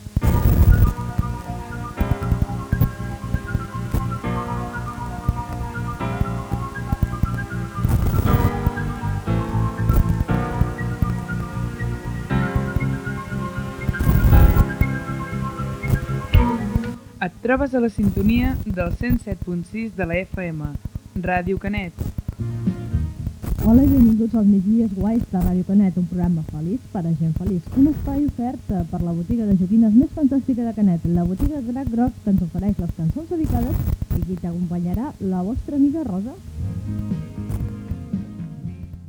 c6f621fb11367ee16753e6a7222e735e1ff4f3a3.mp3 Títol Ràdio Canet Emissora Ràdio Canet Titularitat Pública municipal Nom programa El migdia és guai Descripció Identificació i inici del programa.